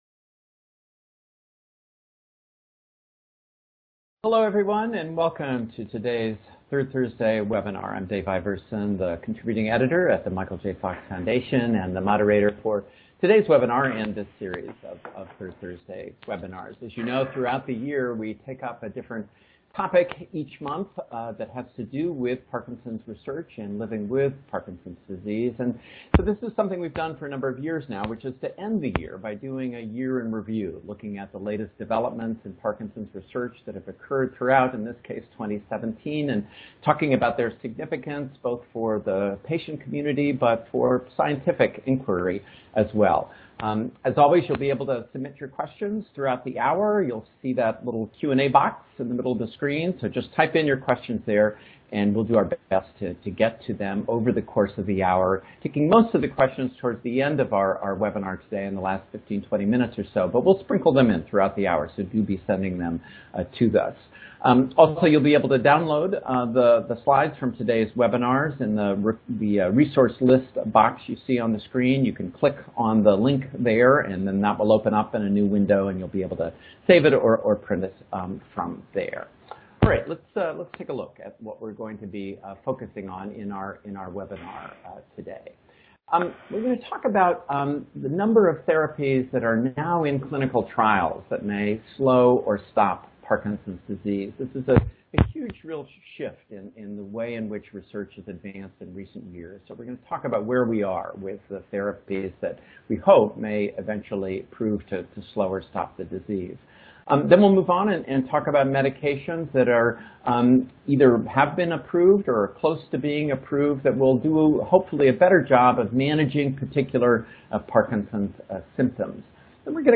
Catch up with this recording and hear our panelists recap new potential Parkinson's treatments in clinical testing.